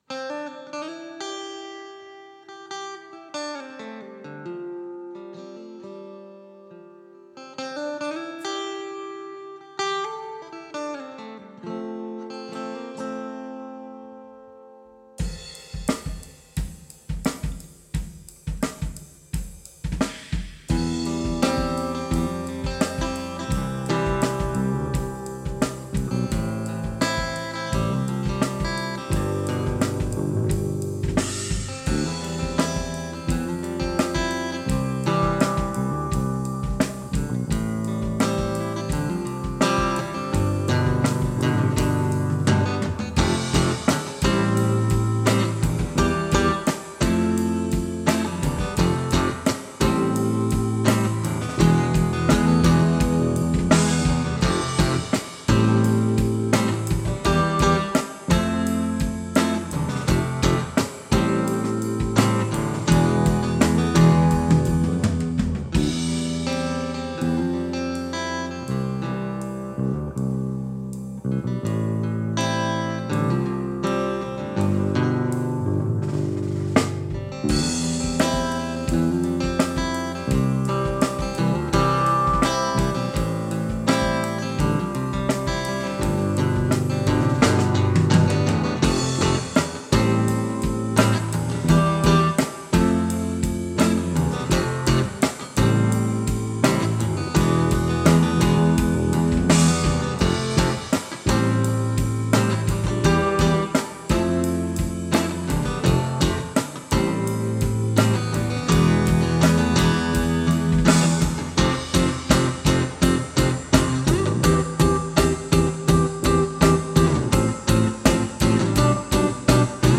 Songwriting
We’re still solidifying our parts so the playing is a bit rough, especially by yours truly.